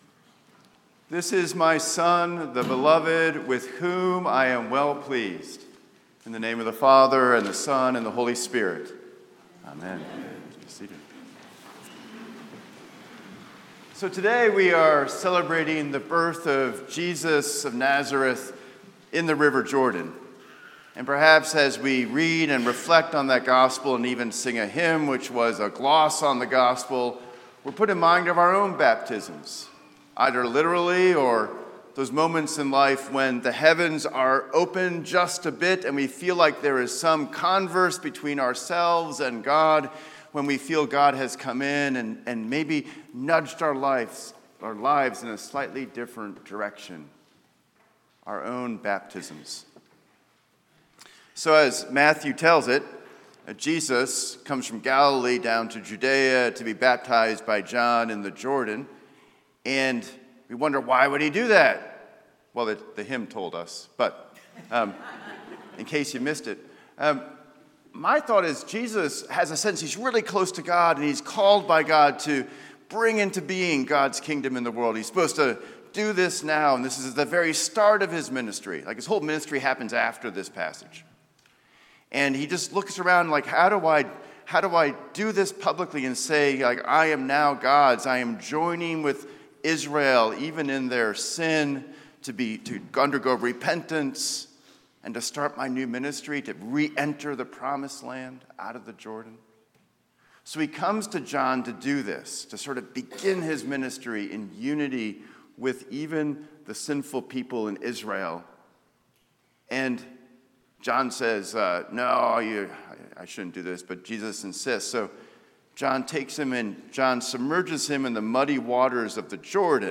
St-Pauls-HEII-9a-Homily-11JAN26.mp3